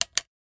Flashlight Turn Off.wav